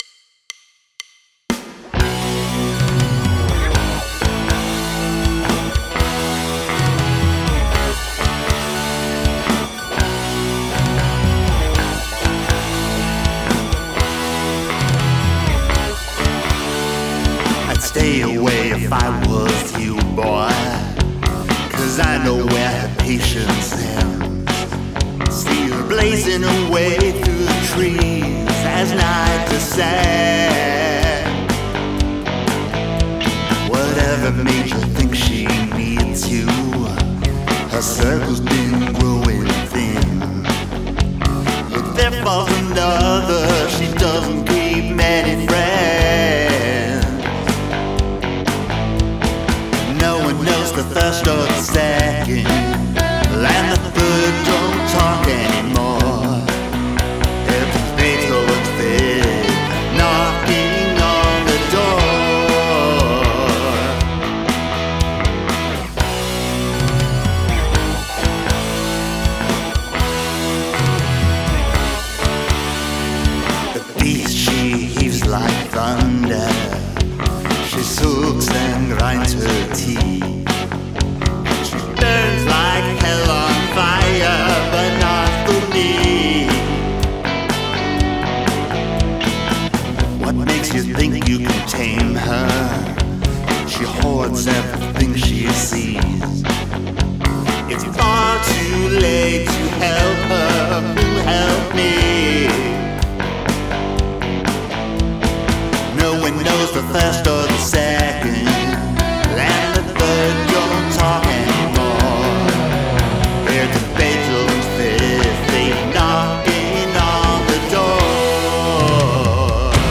Artist Name: FiftyDegrees   Title: Beethoven’s Fifth   Genre: Rock   Rating:
An interesting rhythm guitar element appears in the back half of each verse; one of the rhythm guitars shifts, adding a subtle 16th note bounce, which helps the song evolve and adds a bit of interest.
The keyboard tracks are relatively sparse but cool. The arpeggiated synth lines in the intro add a nice counterpoint to the main guitar riff, with a bell-like point on the notes ensuring that the keyboards arent overwhelmed by it.
The guitar sounds are spot on, as is the way the drums sit in the mix.
Of special note, of course, is the wah-wah guitar solo; the world needs more of that!
But on repeated listening, I wished that the arpeggiated synth line was a bit louder.